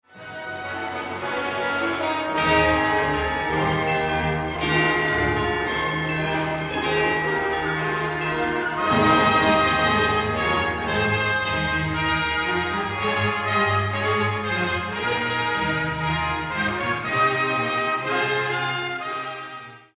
Marsz ponownie jest bardzo powolny, ale o wiele bardziej muskularny, o wiele bardziej potężny i pełen blasku:
Wspaniale brzmią tu dzwony i trąbki:
Otto Klemperer, Janet Baker, Heather Harper, Symphonieorchester des Bayerischen Rundfunks, Bayerisches Rundfunk Chor, 1965, I – 20:35, II – 10:39, III – 12:00, IV – 4:07, V – 32:19 [79:39], Warner